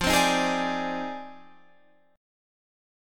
Listen to Fm13 strummed